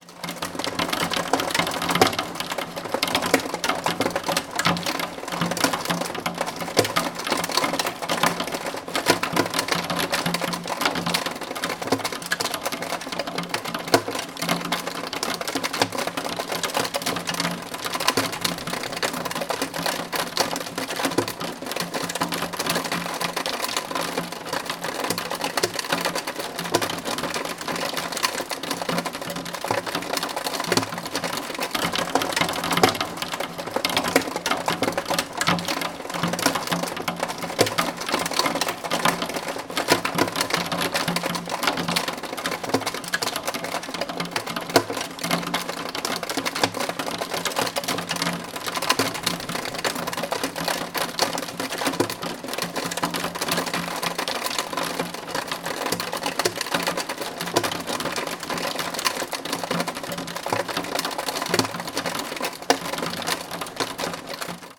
ftus_rain_hail_hit_window_Germany